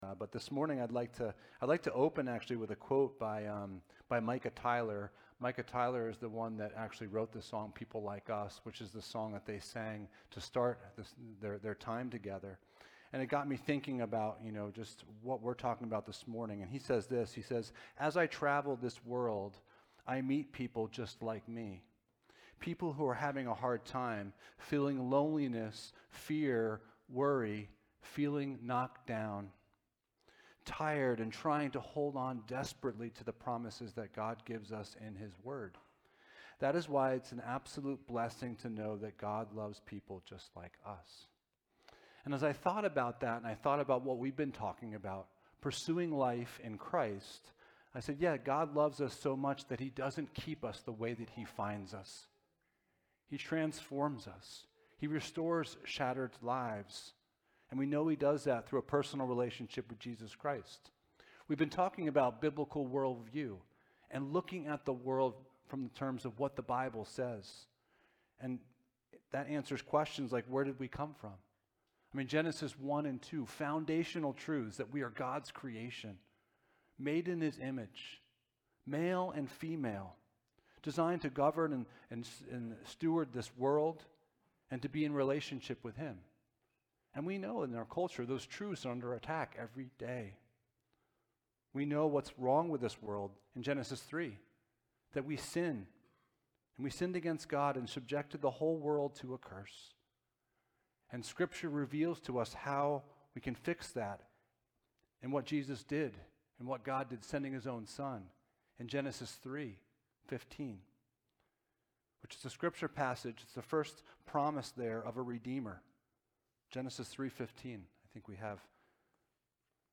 Passage: Colossians 2:6-8 Service Type: Sunday Morning